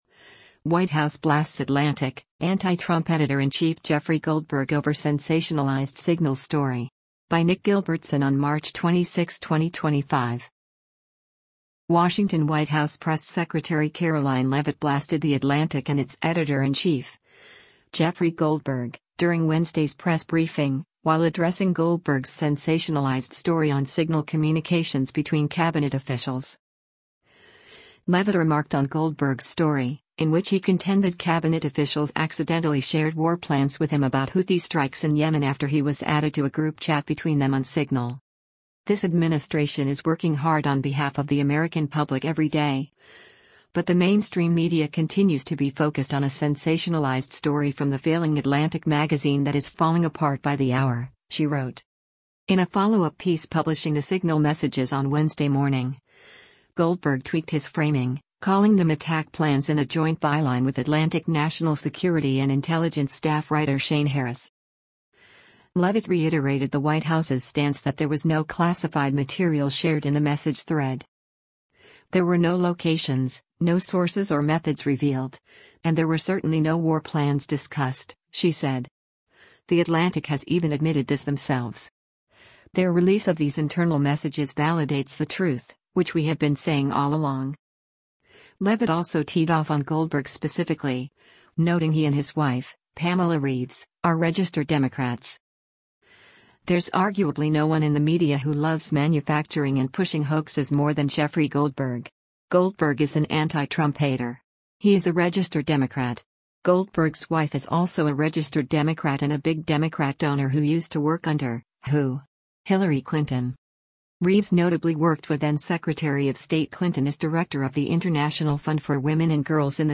WASHINGTON–White House Press Secretary Karoline Leavitt blasted the Atlantic and its editor-in-chief, Jeffrey Goldberg, during Wednesday’s press briefing, while addressing Goldberg’s “sensationalized story” on Signal communications between Cabinet officials.